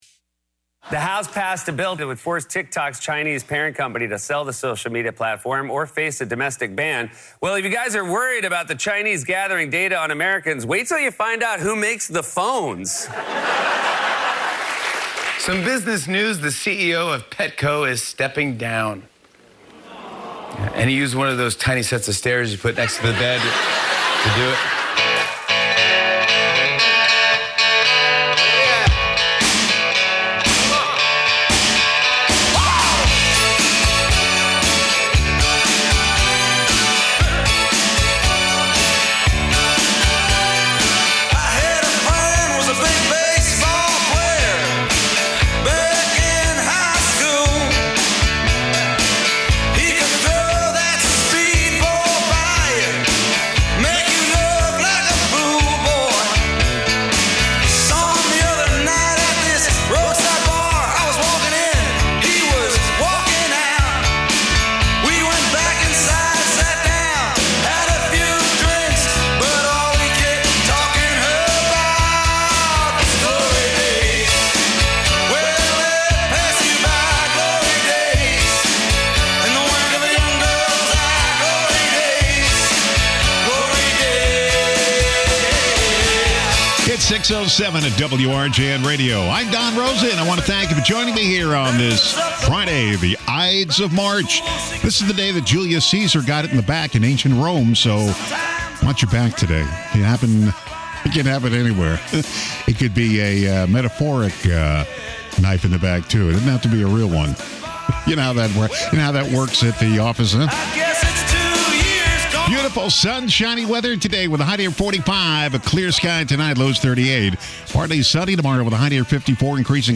good morning wake-up fun, along with local news, weather, sports and comedy